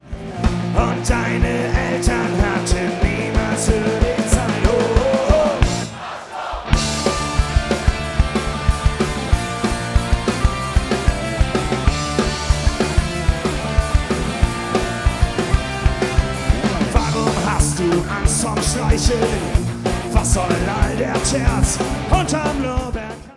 (LIVE!)